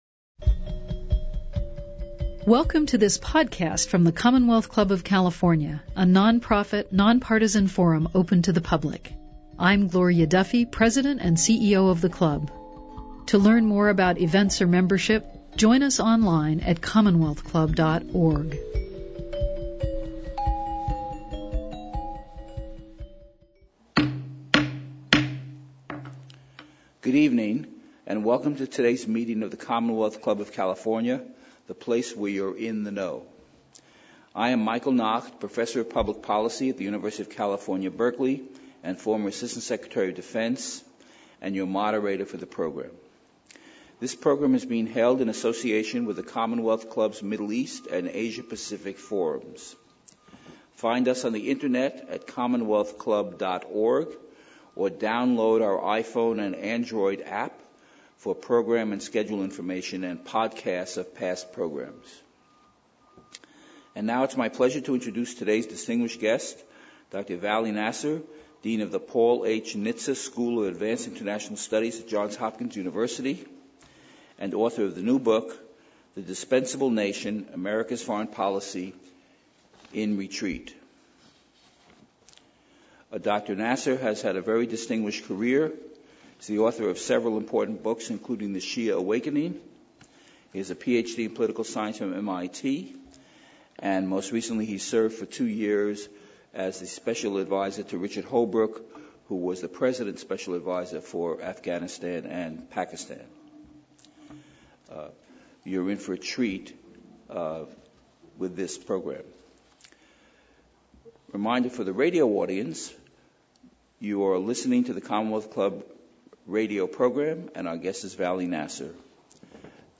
Dean, Paul H. Nitze School of Advanced International Studies, John Hopkins University, Author, The Dispensable Nation Michael Nacht, Ph.D., Professor and former Dean, Goldman School of Public Policy, U.C. Berkeley; Former Assistant Secretary of Defense for Global Strategic Affairs, Obama Administration - Moderator